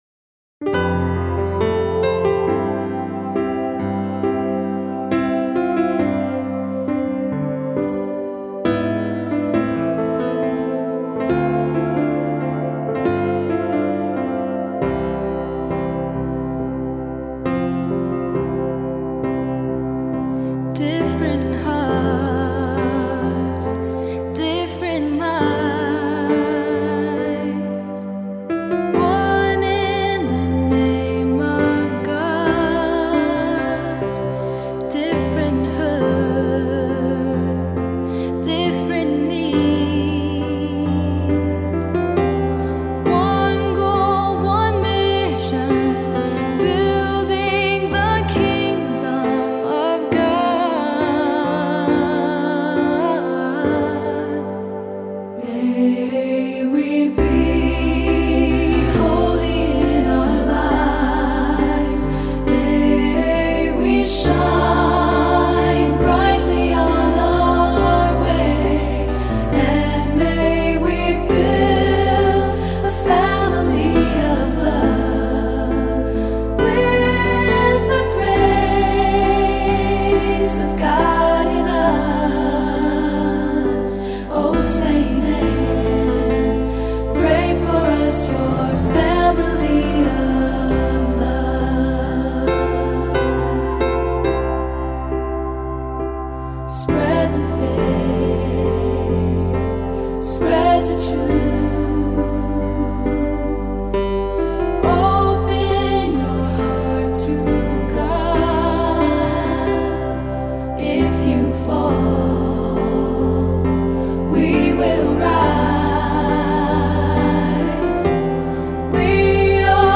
Voicing: "SAB","Cantor","Assembly"